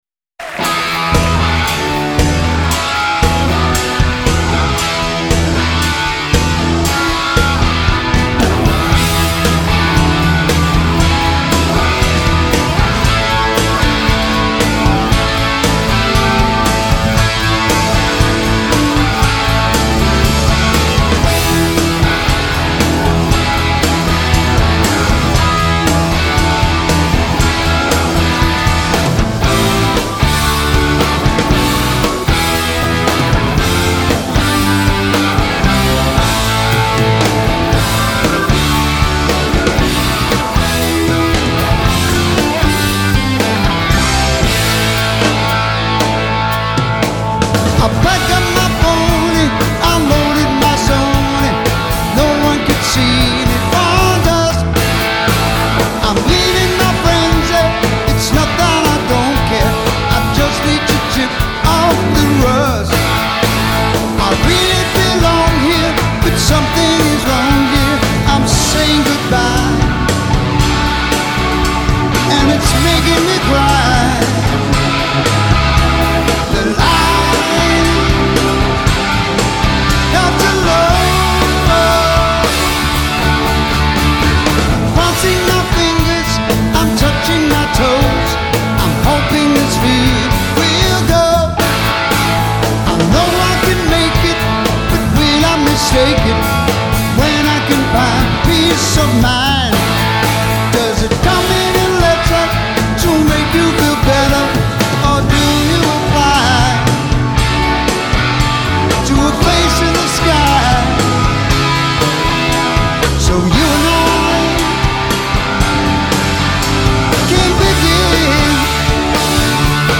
slide guitar solo